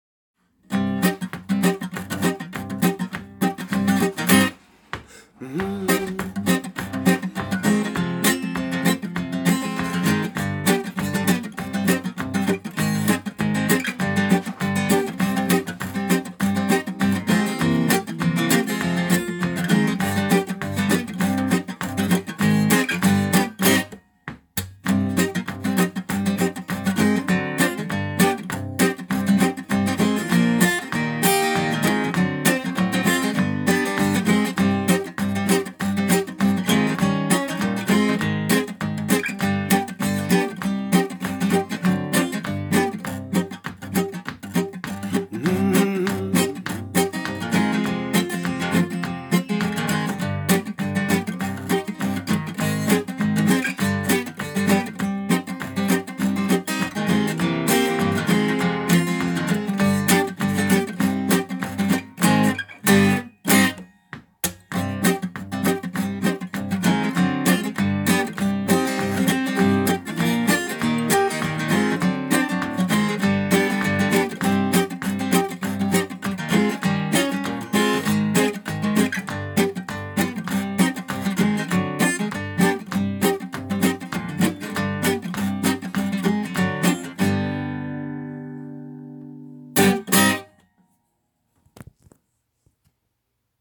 (en do mineur)